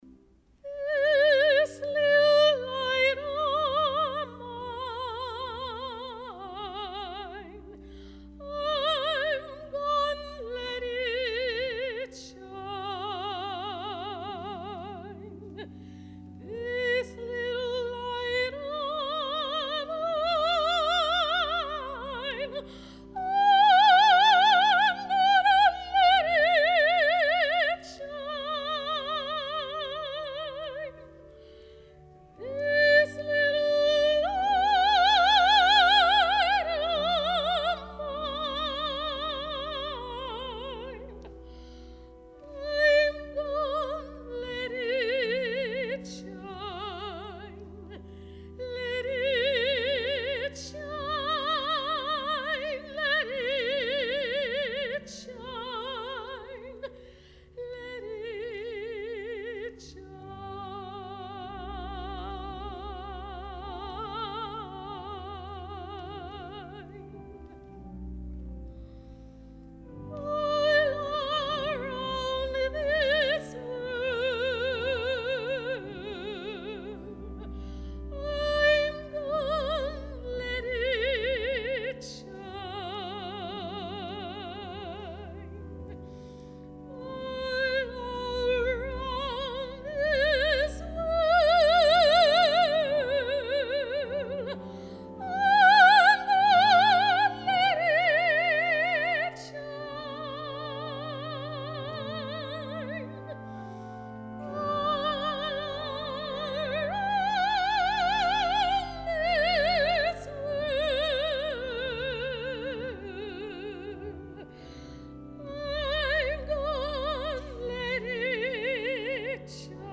soprano
organ